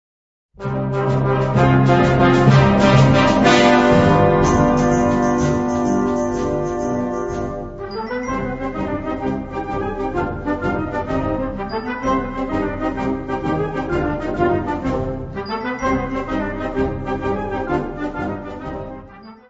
Catégorie Harmonie/Fanfare/Brass-band
Instrumentation Ha (orchestre d'harmonie)